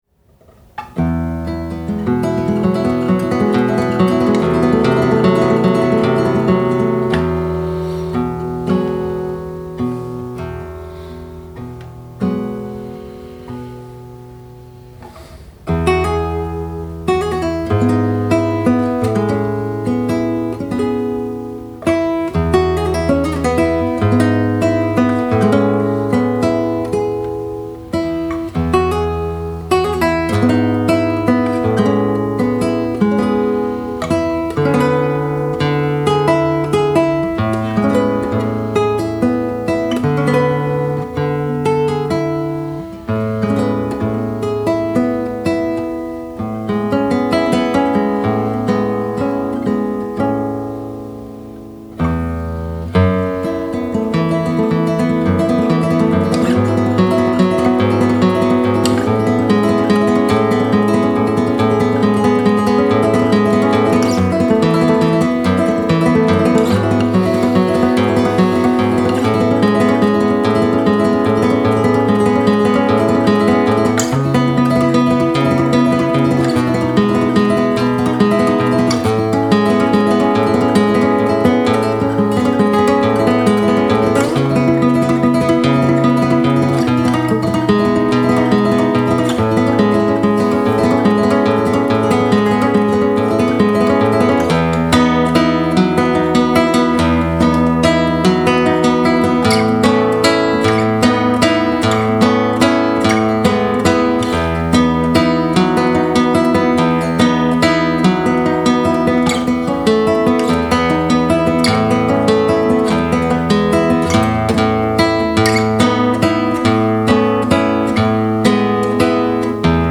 A performance in honor of the composer
with its shimmering arpeggios and elegant melody